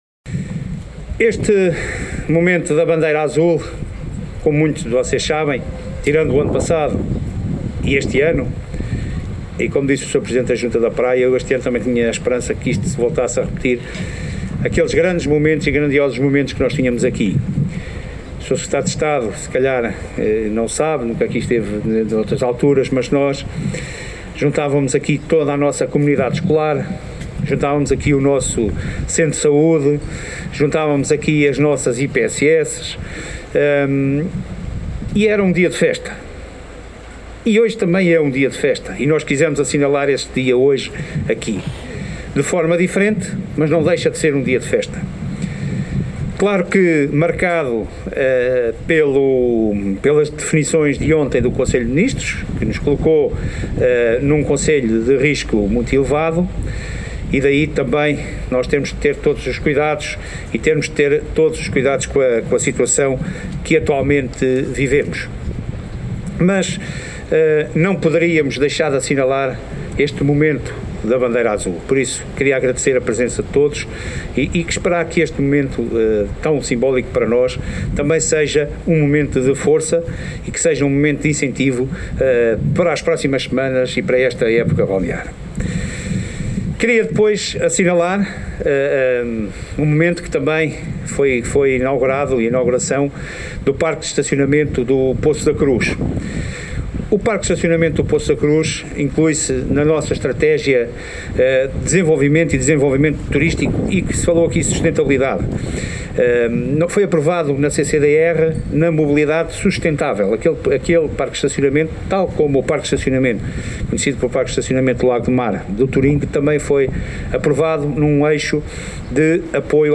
Nesta sessão, o presidente da Câmara de Mira, Raul Almeida, falou sobre a importância desta atribuição da Bandeira Azul e também sobre a situação epidemiológica no concelho: